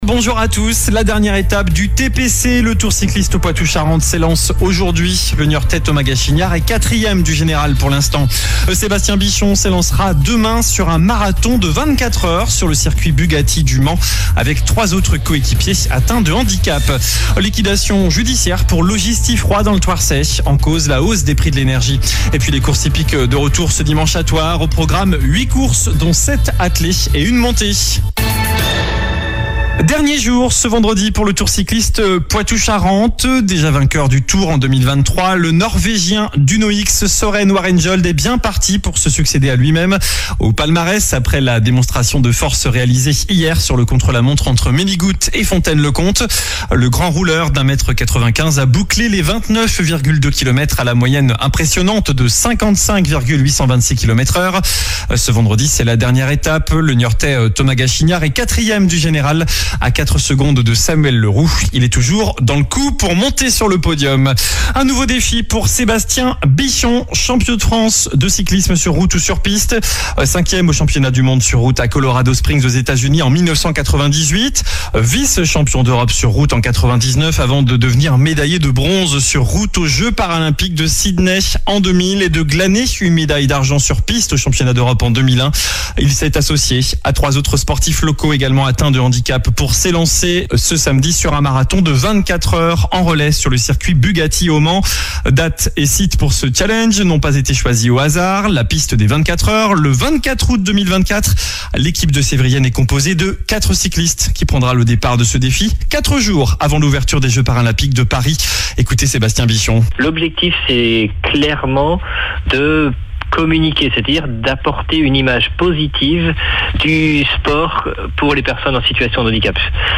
JOURNAL DU VENDREDI 23 AOÛT